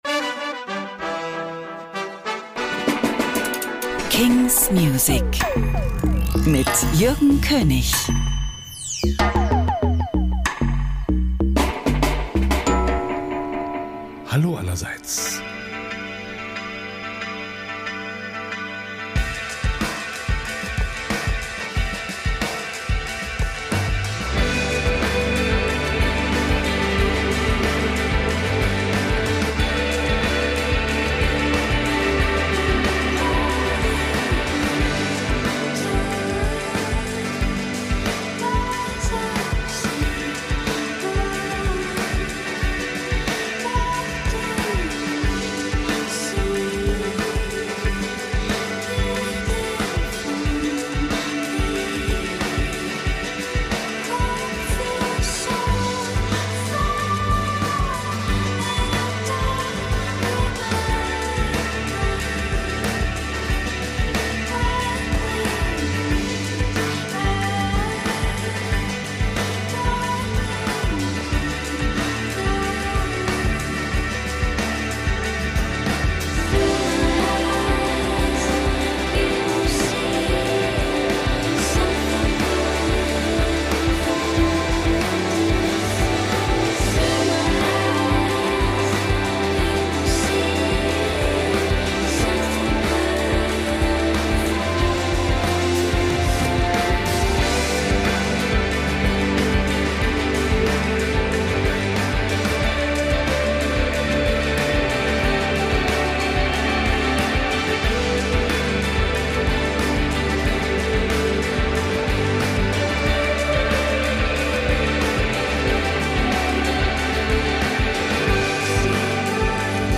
brandnew indie & alternative releases